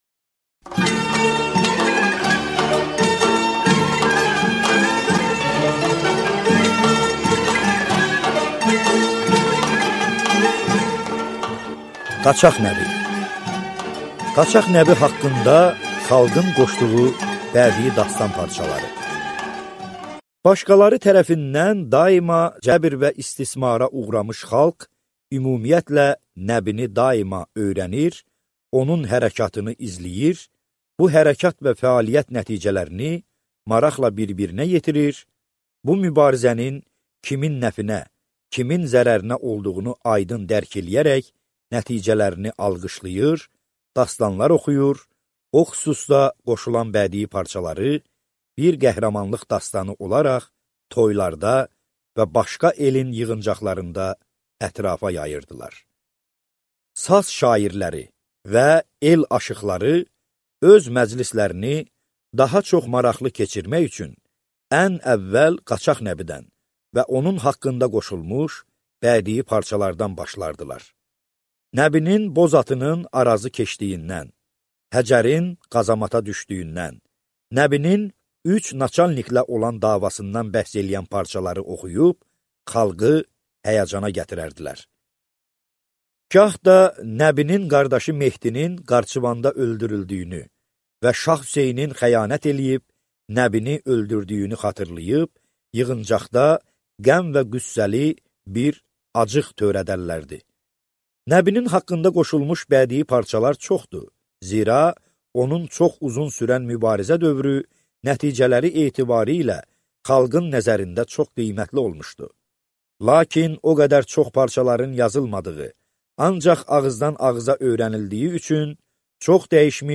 Аудиокнига Qaçaq Nəbi | Библиотека аудиокниг